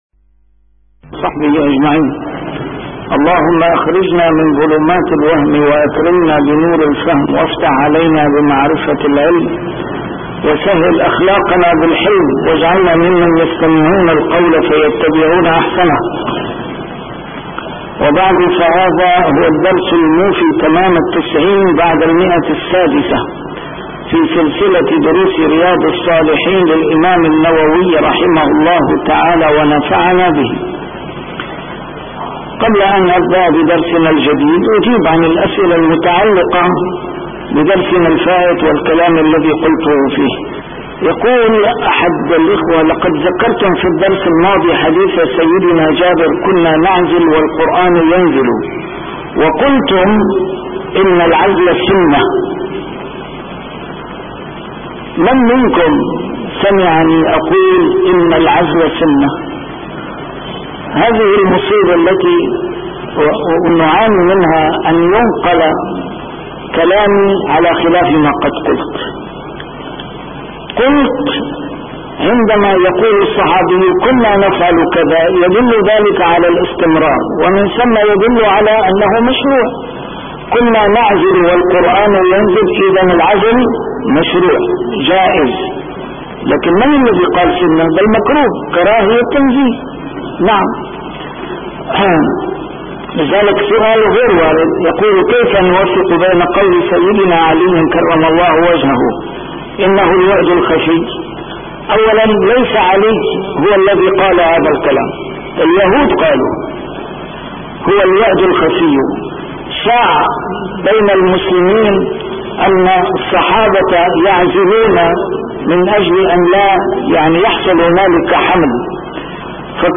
A MARTYR SCHOLAR: IMAM MUHAMMAD SAEED RAMADAN AL-BOUTI - الدروس العلمية - شرح كتاب رياض الصالحين - 690- شرح رياض الصالحين: آداب السلام